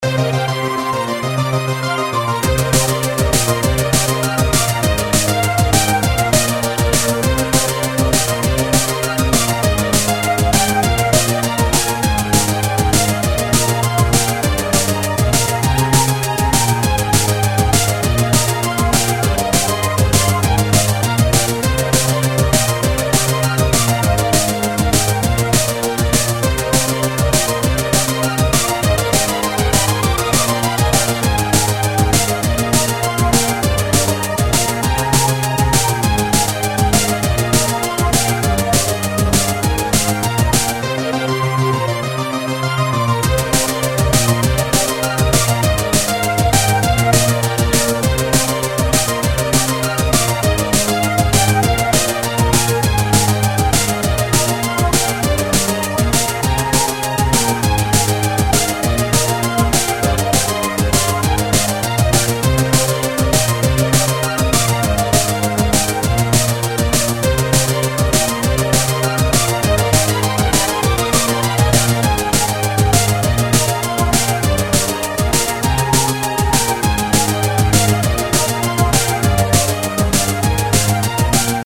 Tech Base Midi Version